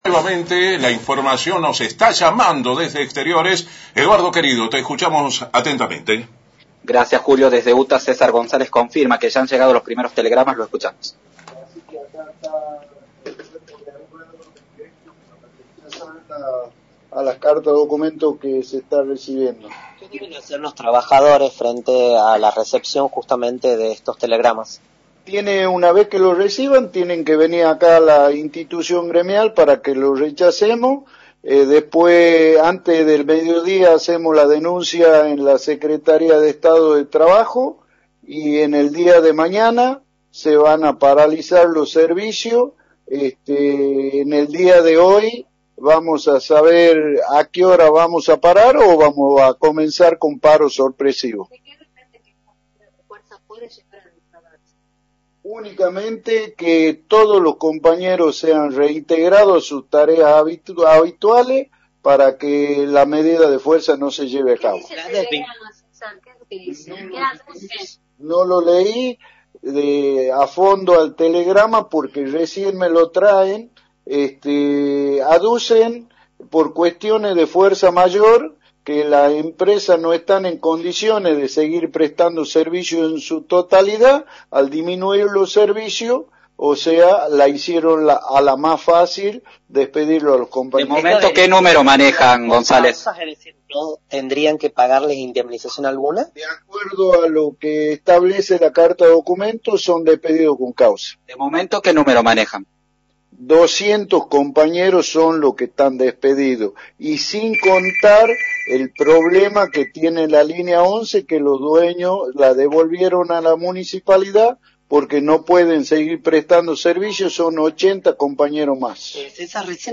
en conferencia de prensa